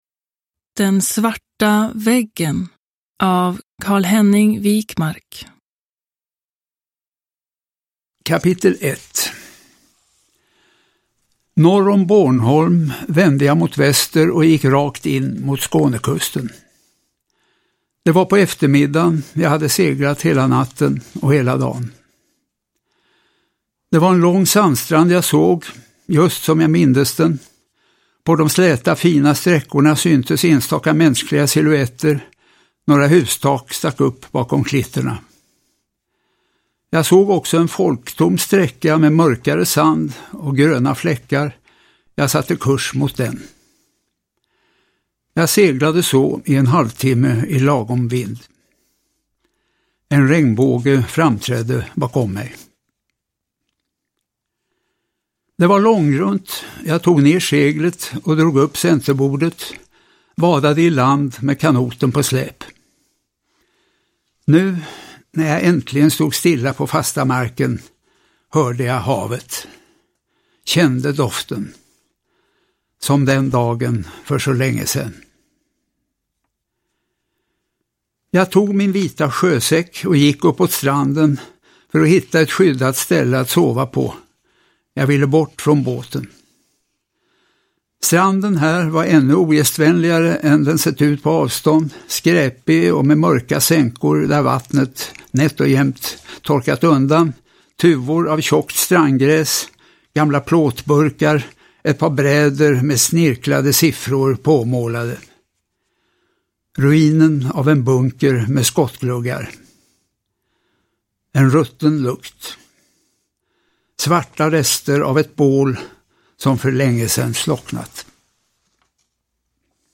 Den svarta väggen : roman – Ljudbok – Laddas ner
Uppläsare: Carl-Henning Wijkmark